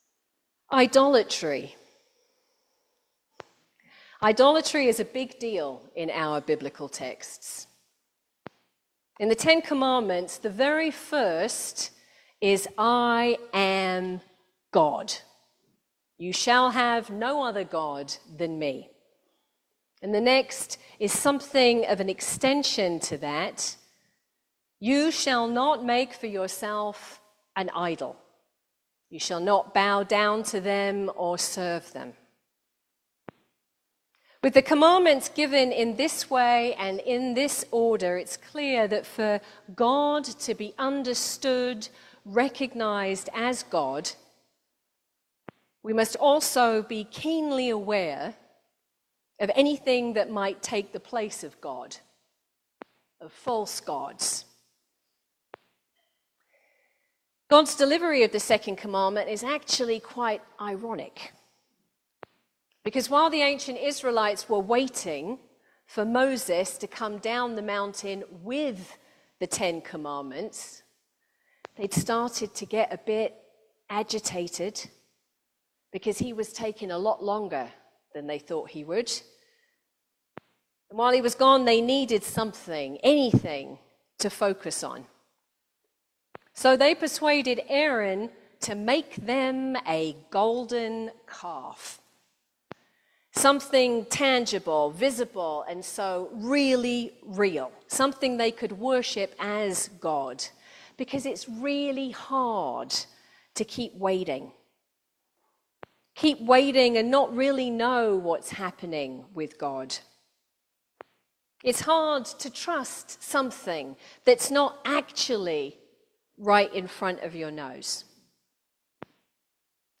Trinity-Sunday-Sermon.mp3